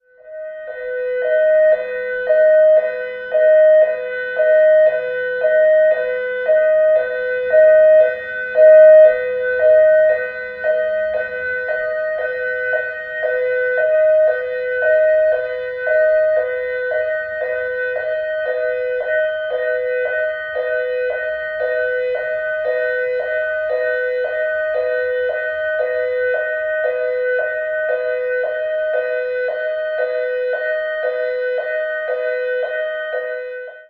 Police Siren: In Tunnel with Various Types.